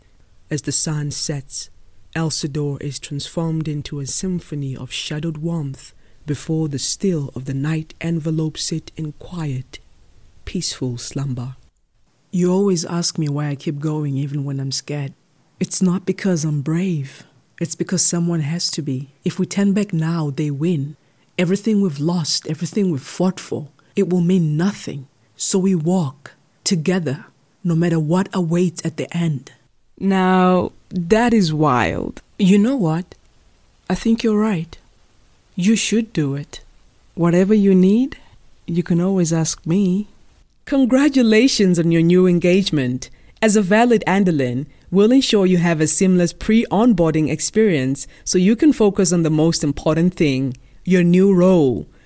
authentic, authoritative, bright, captivating, commercial, empathic, resonant, soothing
With a naturally warm and articulate tone, she delivers everything from soulful narration to vibrant commercial reads with clarity and purpose.